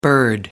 Bird-english.mp3